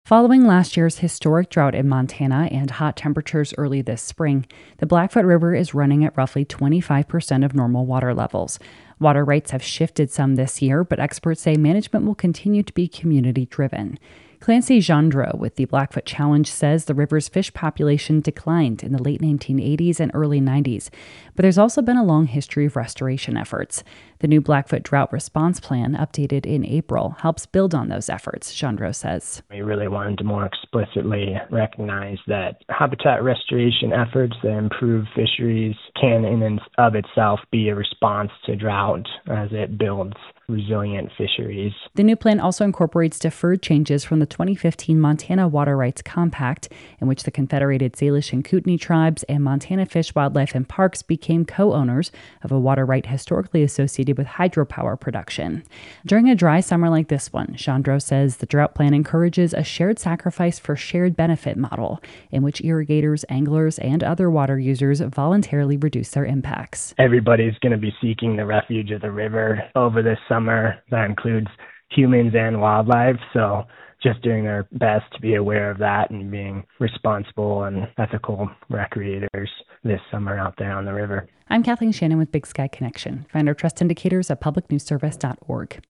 Producer